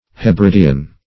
Search Result for " hebridian" : The Collaborative International Dictionary of English v.0.48: Hebridean \He*brid"e*an\, Hebridian \He*brid"i*an\, a. Of or pertaining to the islands called Hebrides, west of Scotland.
hebridian.mp3